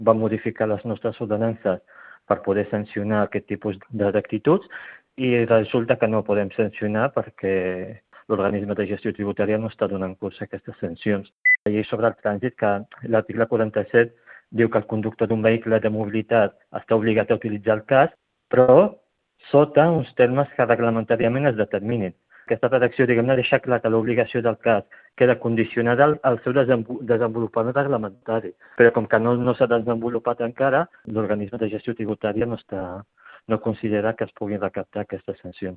Però no es poden multar les infraccions perquè la norma general de la DGT només ho recomana i encara no s’ha desenvolupat el reglament que en concreti l’obligatorietat. Així ho ha explicat el tinent d’Alcaldia de Seguretat i Mobilitat, Soufian Laroussi, en declaracions a Ràdio Calella TV.